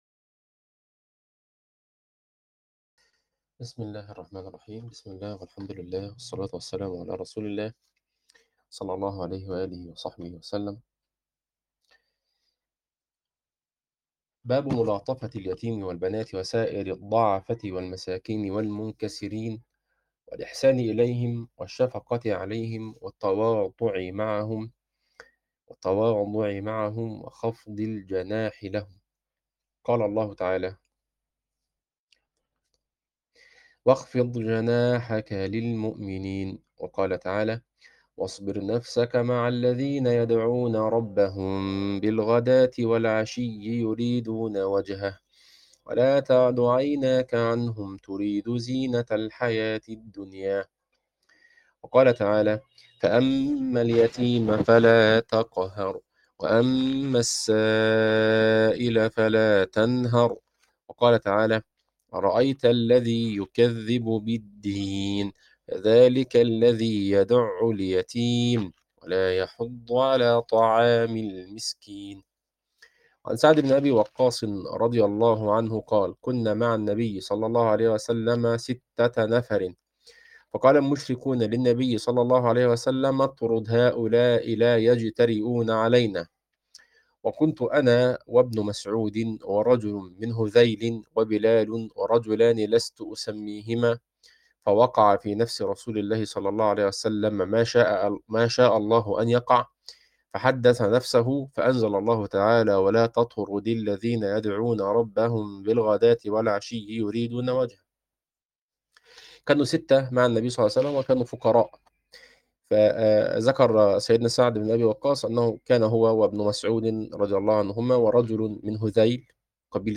عنوان المادة الدرس 9 | دورة كتاب رياض الصالحين تاريخ التحميل الجمعة 27 يونيو 2025 مـ حجم المادة 59.73 ميجا بايت عدد الزيارات 114 زيارة عدد مرات الحفظ 72 مرة إستماع المادة حفظ المادة اضف تعليقك أرسل لصديق